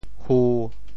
“俘”字用潮州话怎么说？
俘 部首拼音 部首 亻 总笔划 9 部外笔划 7 普通话 fú 潮州发音 潮州 hu1 文 中文解释 俘 <动〉 (形声。
hu1.mp3